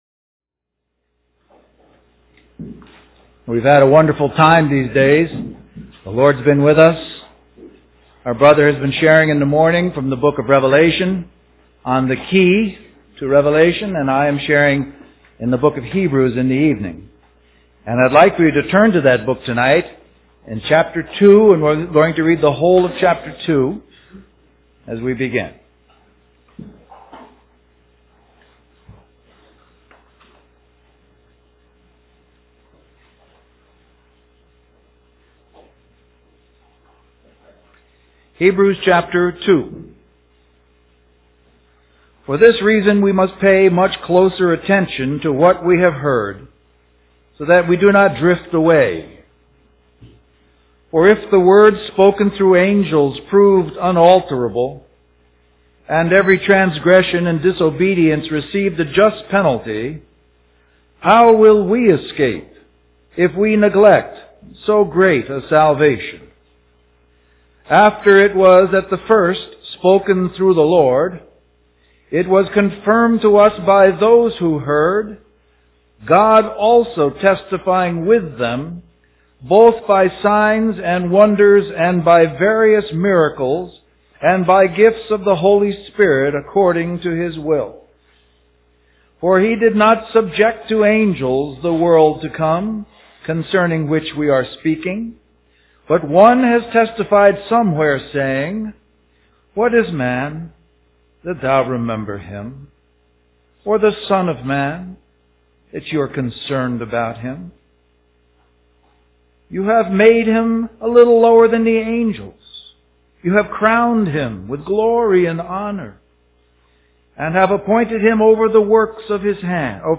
Western Christian Conference